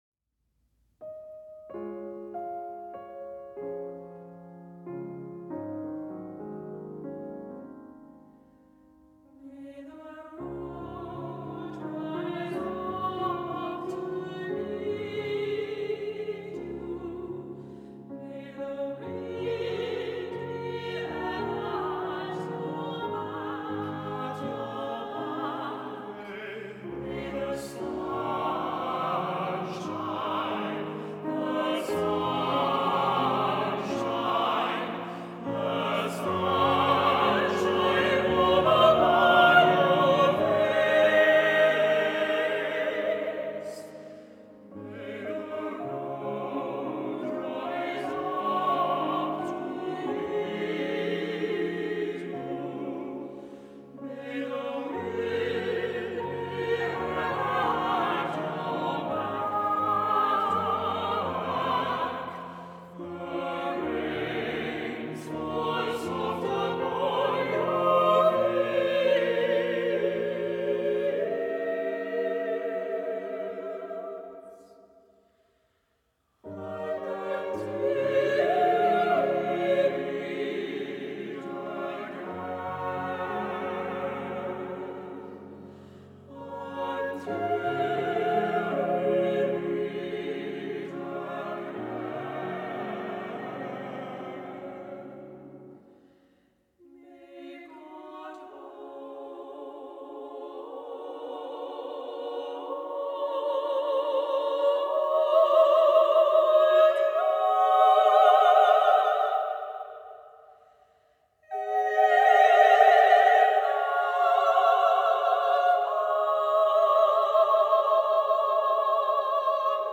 2 part accompanied